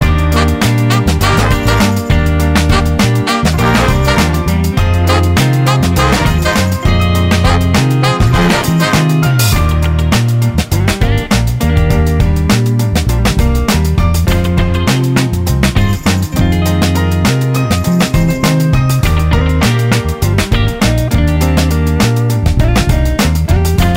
'68 Version Pop (2000s) 3:57 Buy £1.50